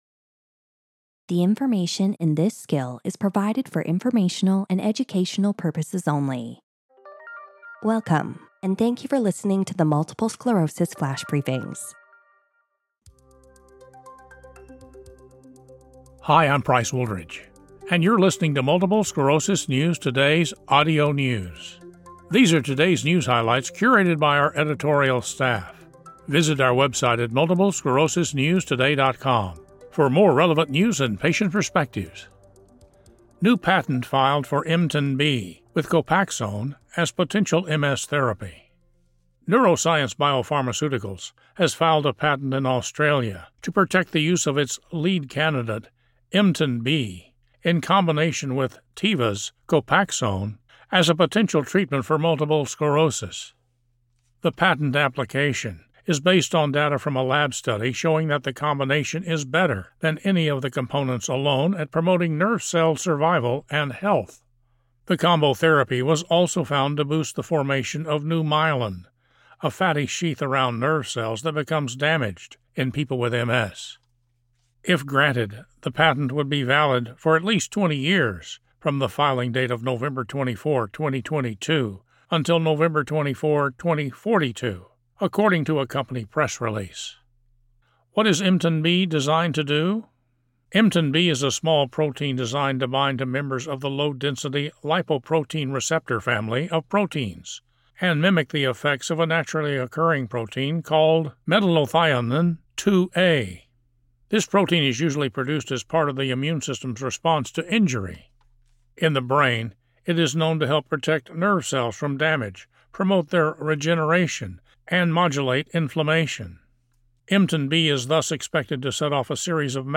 reads the news article about a patent that has been filed in Australia for EmtinB in combination with Copaxone as a potential MS treatment.
He also reads about a new CAR T-cell therapy that eliminates the self-reactive immune cells that drive MS without destroying healthy immune cells.